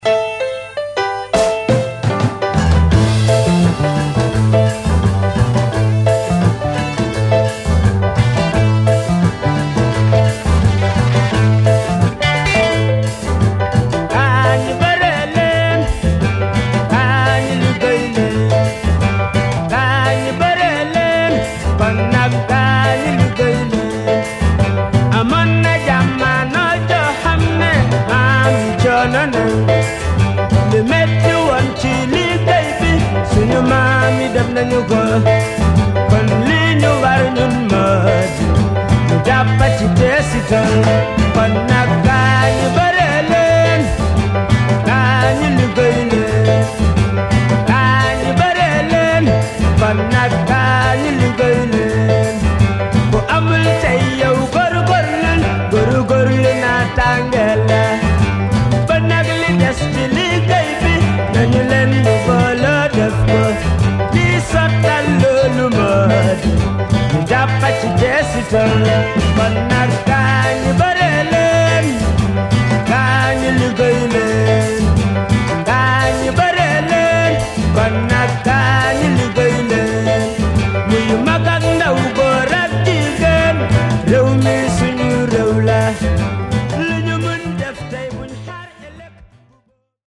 A compilation of Gambian psych sensation